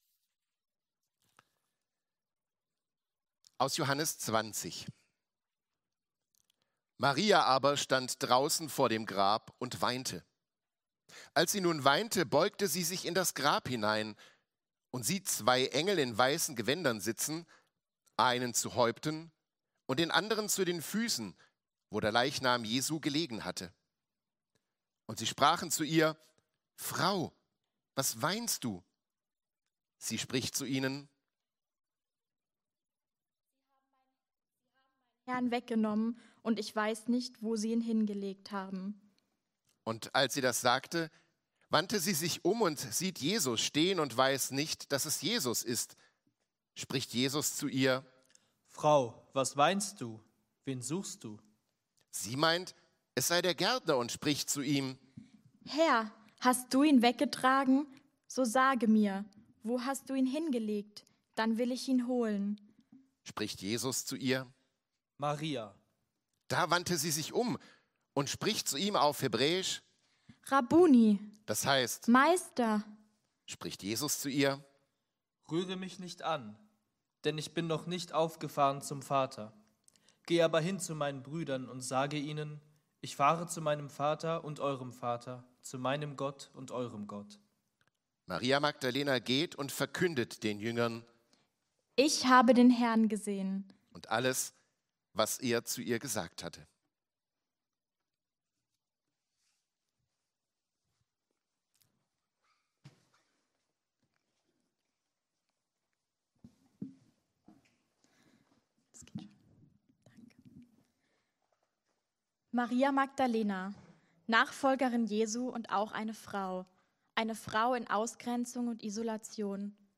„Ostern ganz persönlich“ – Gottesdienst mit Abendmahl